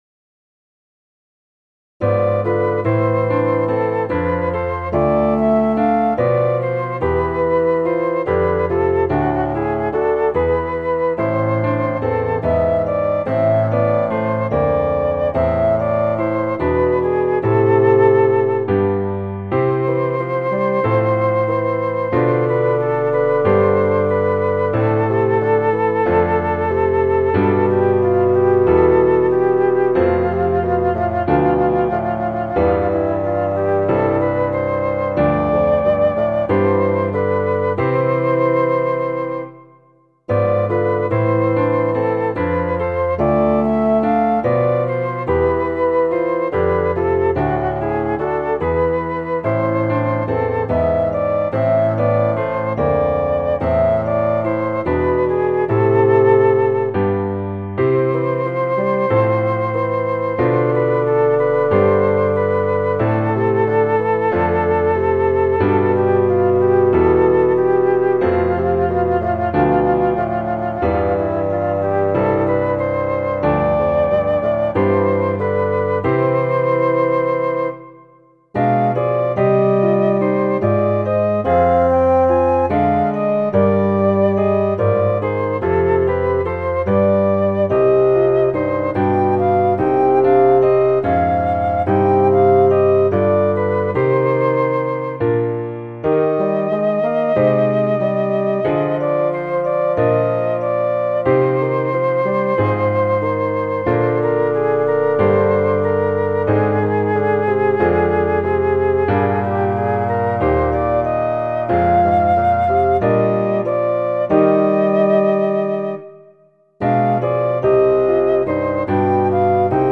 ひたすらだらけた気分を表現したかった曲です。
• 楽器：フルート、バスーン（ファゴット）、ピアノ
• 主調：イ短調
• 拍子：5/8拍子、4/4拍子
• 速度：八分音符＝144、四分音符＝92
• 楽式：ソナタ形式